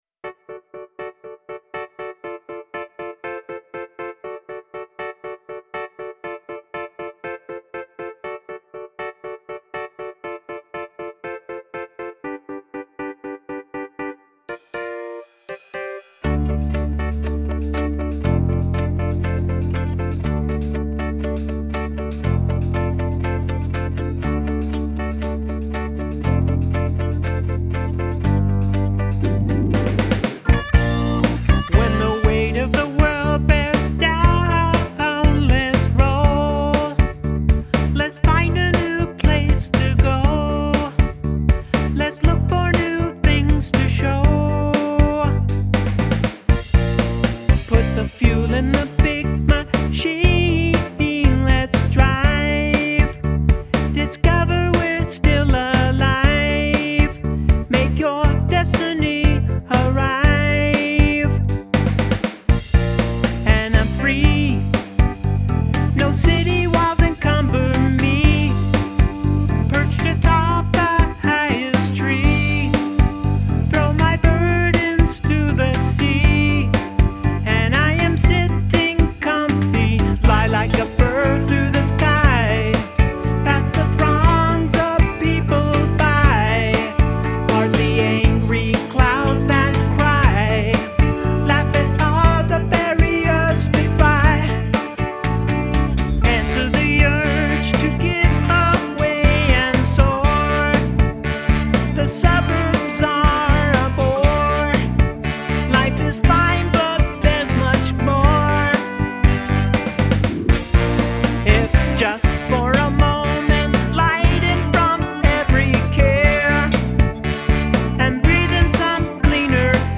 mid-to-late seventies rock